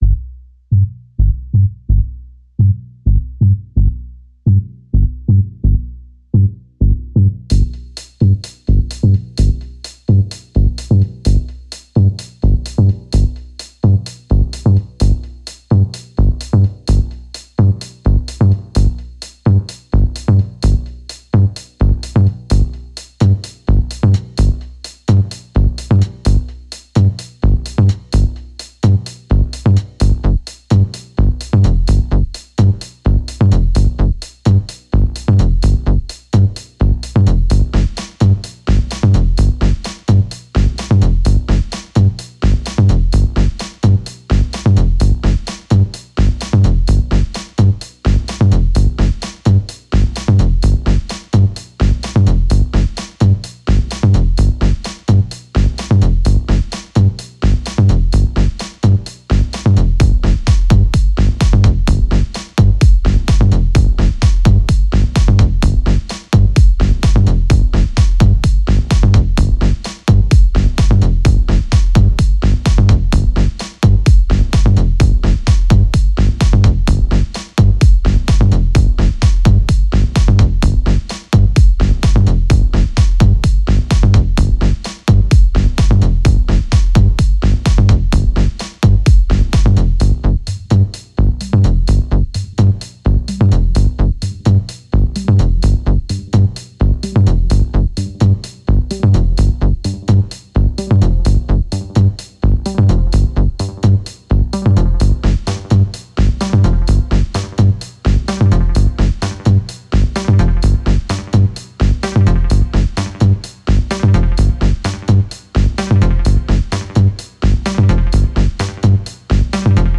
Mosque Divinity Dakar in Senegal